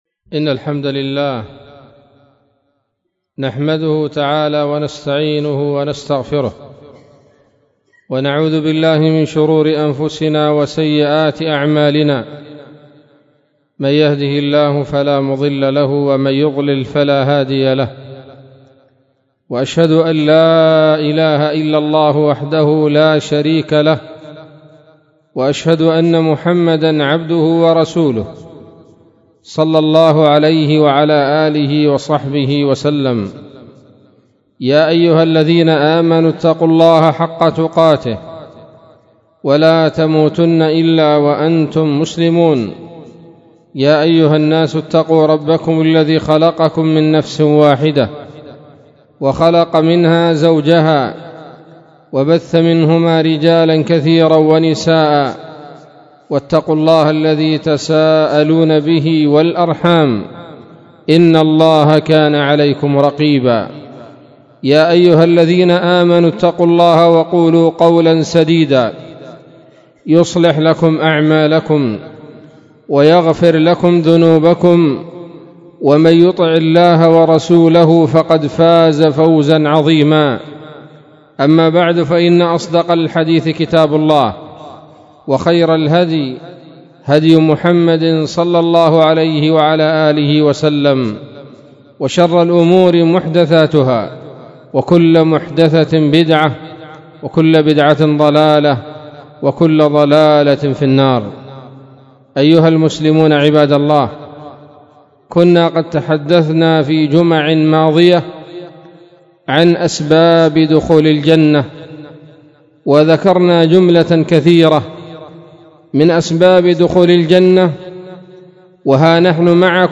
خطبة بعنوان